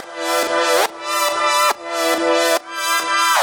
Index of /musicradar/french-house-chillout-samples/140bpm/Instruments
FHC_Pad A_140-E.wav